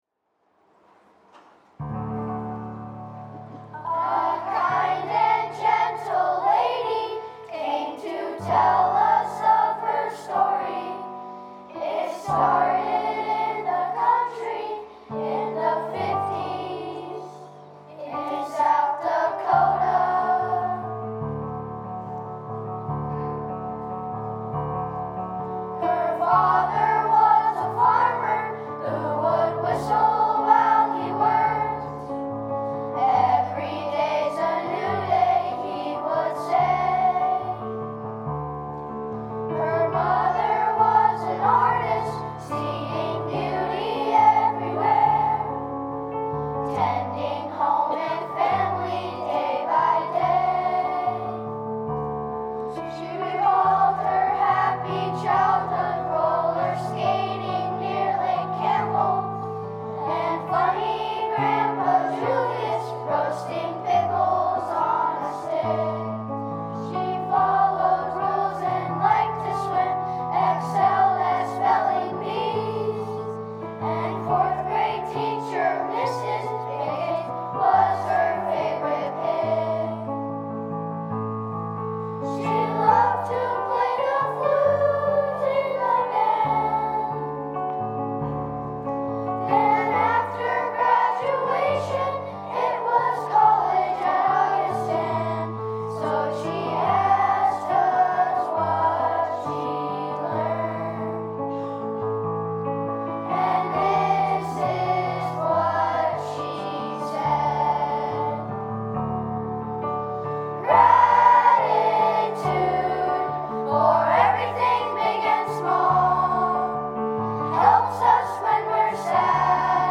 5th Grade Class